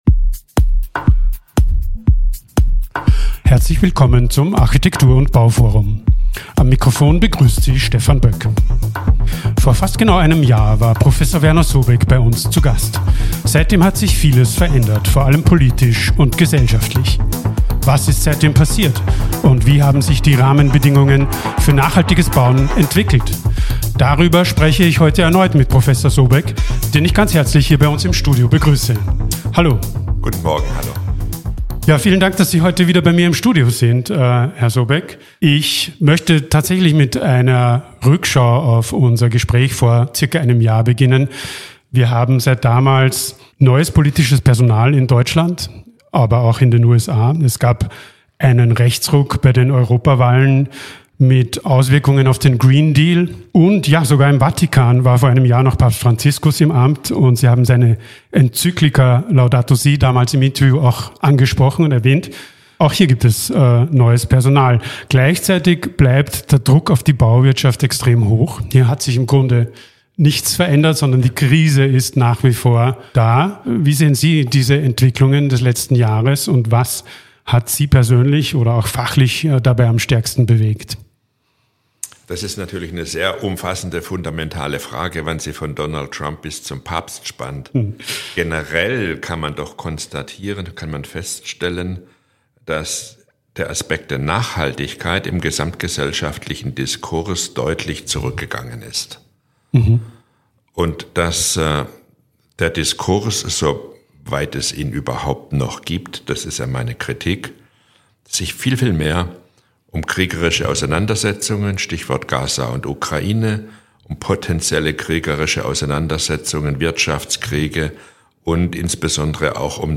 Im Gespräch fordert Sobek verbindliche Emissionsziele statt technischer Einzelvorgaben, eine gerechtere Strompreisstruktur und mehr Mut zur Vereinfachung gesetzlicher Regelungen.